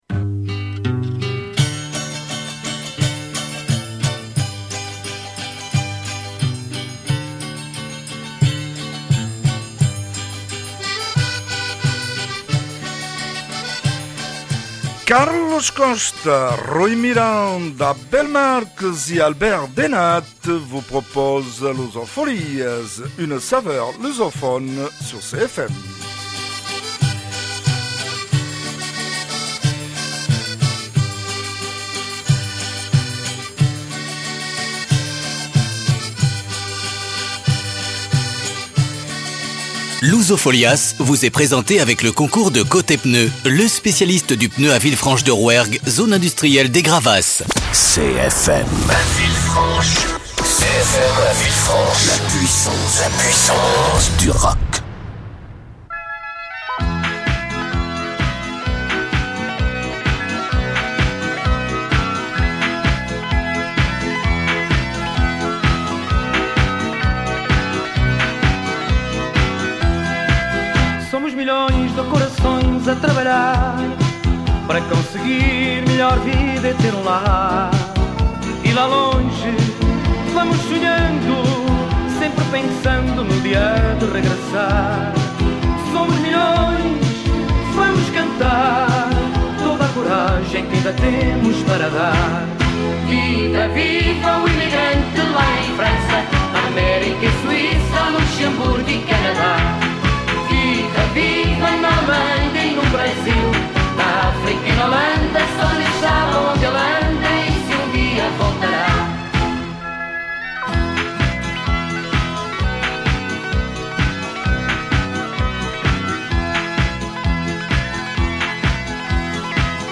Pour votre saveur portugaise du jour, on se régale avec une recette de calamar à l’étouffée, un voyage dans les lieux sacrés du Portugal, une chronique autour de l’argent, et le plein de musique portugaise !